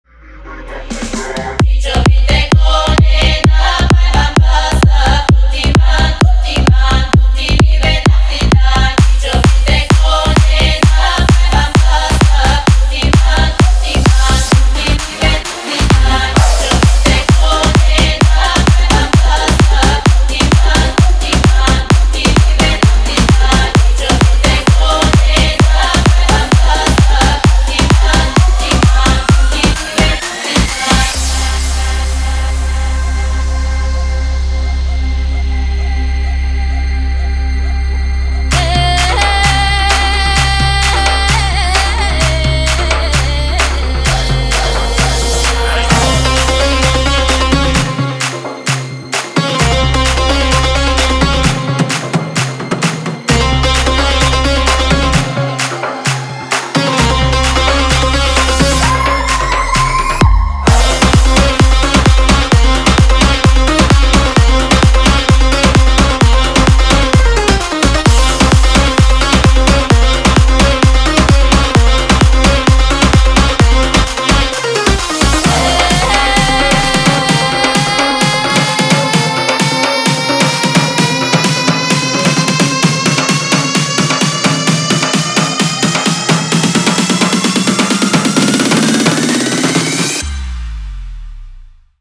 • Качество: 320, Stereo
dance
club
восточные